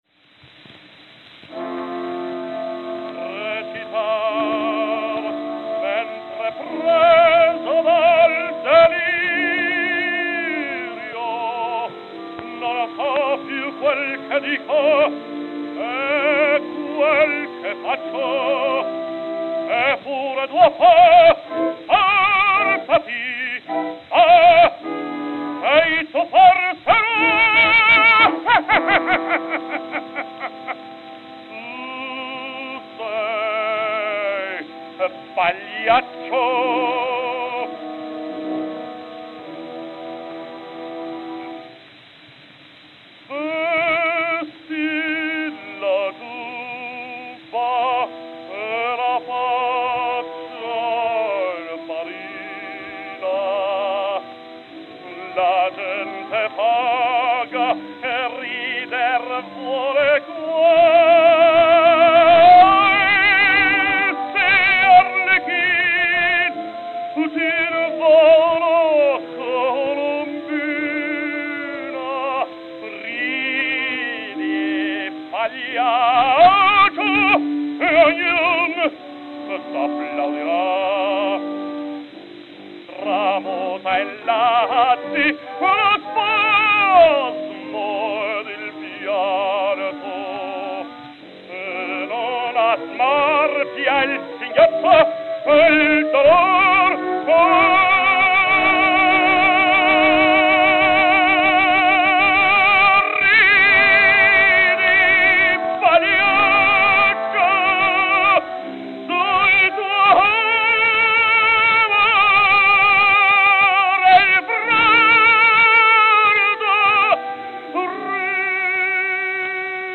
Victor Red Seal 78 RPM Records
Enrico Caruso
New York, New York